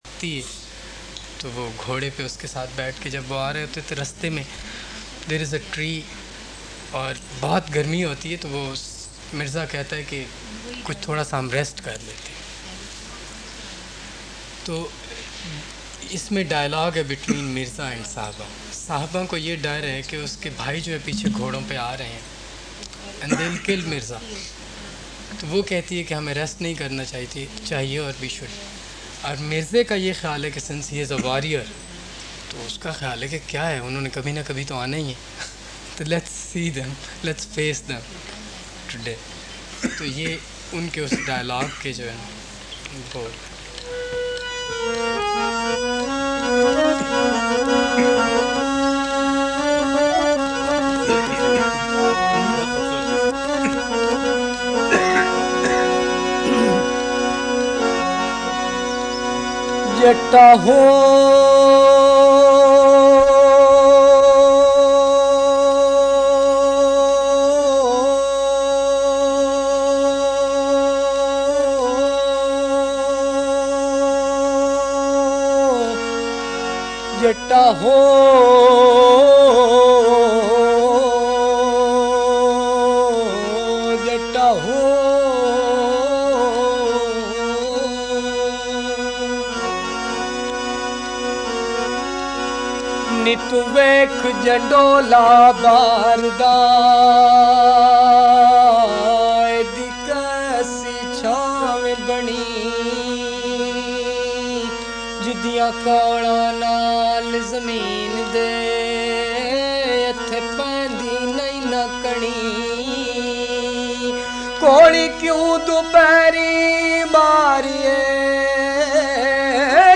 It was powerful and carried me into a heavenly abode..